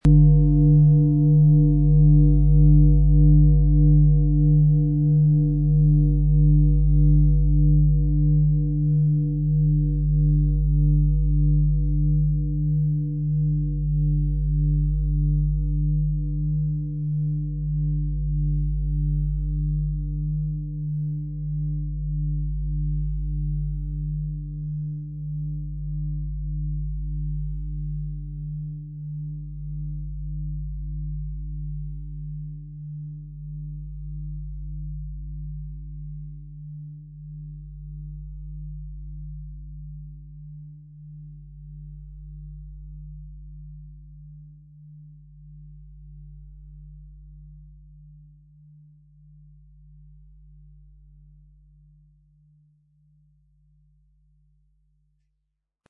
XXXL-Fußreflexzonen-Klangschale weite Klänge für tiefe Entspannung - ca. Ø 51 cm, 10,68 kg, mit Klöppel, bis ca. Schuhgröße 44
Ein sanfter Schlag genügt, und die Schale entfaltet tiefe Töne, die dich erden und entspannen.
So trägt sie eine einzigartige Klangsignatur in sich - lebendig, authentisch und voller Charakter.
MaterialBronze